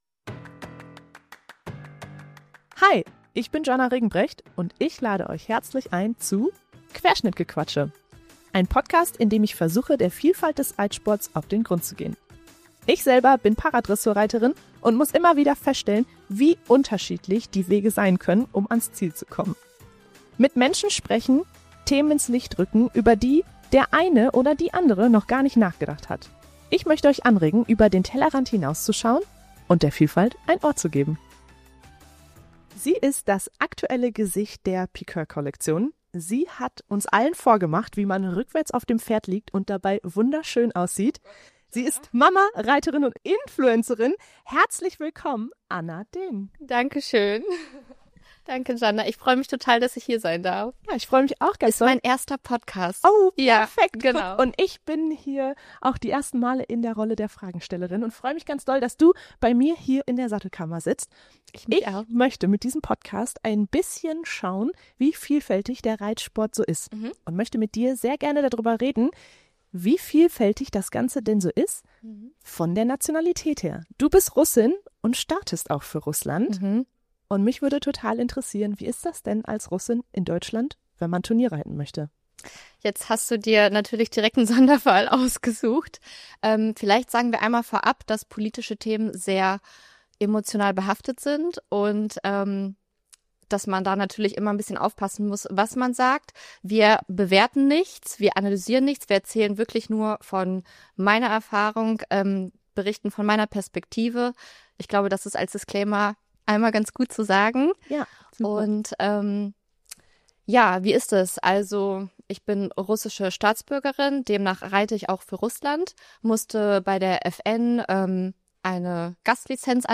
Wie das ist, ob das aktuell geht und wie vielfältig der Reitsport im Bezug auf Nationalitäten Vielfältigkeit ist habe ich sie in meiner Sattelkammer gefragt.